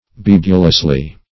Search Result for " bibulously" : The Collaborative International Dictionary of English v.0.48: Bibulously \Bib"u*lous*ly\, adv. In a bibulous manner; with profuse imbibition or absorption.